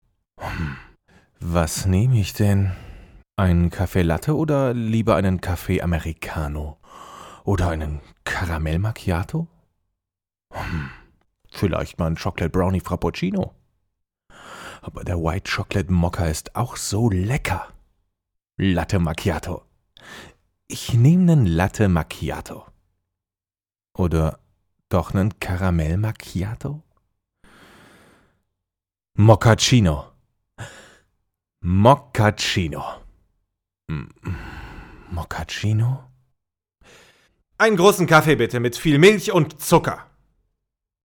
deutscher Sprecher, off Sprecher, TV Radio Moderator, Werbesprecher, Trailer, Hörbuch, Doku, Videospiele, div.
Sprechprobe: eLearning (Muttersprache):